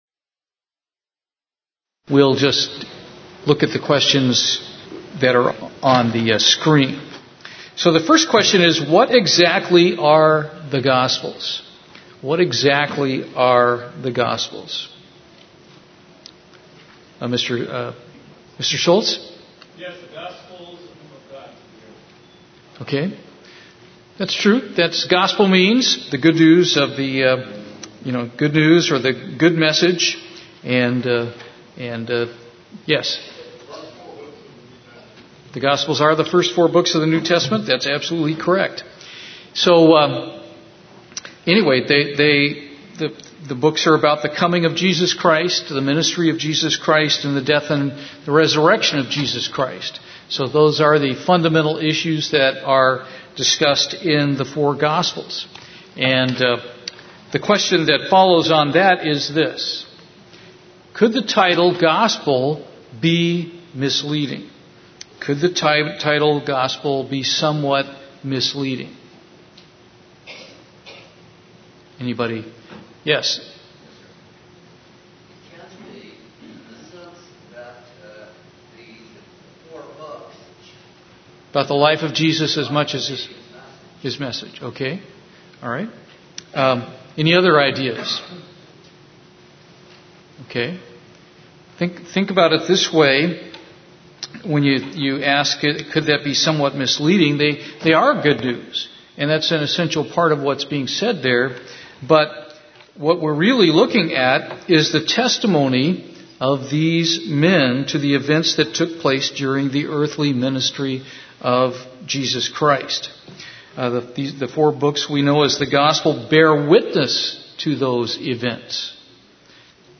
Bible Study
Given in Houston, TX